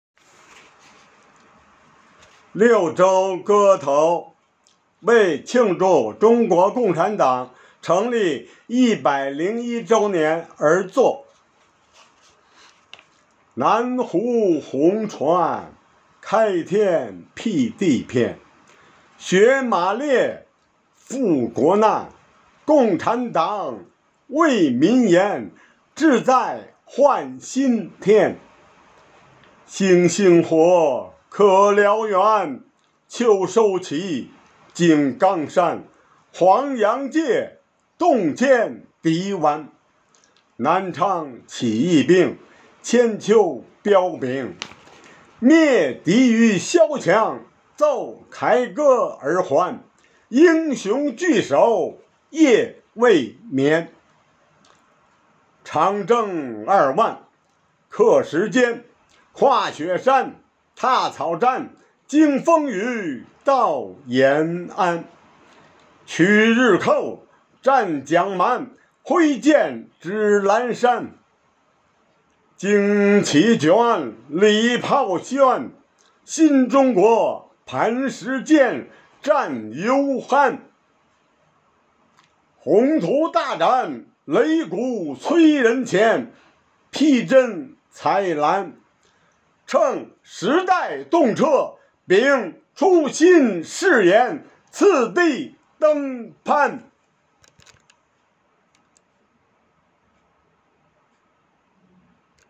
生活好课堂朗读志愿者